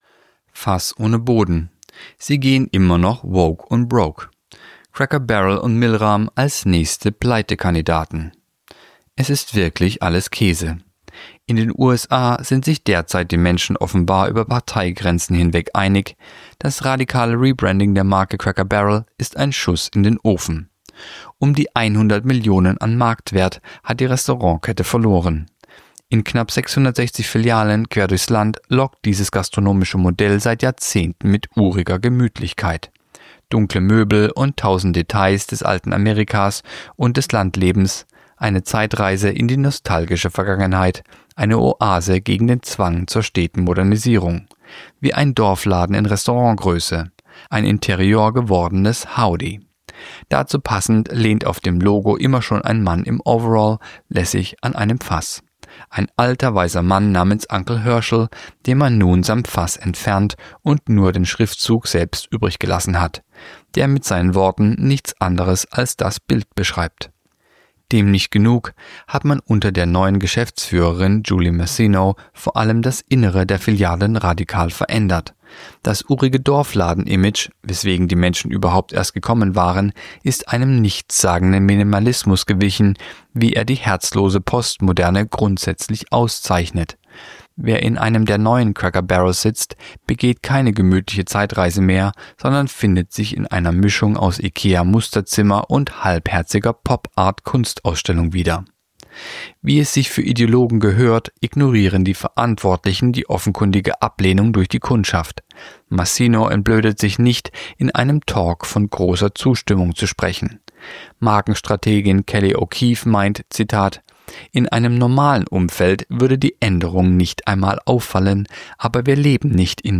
Kolumne der Woche (Radio)Sie gehen immer noch „woke“ und „broke“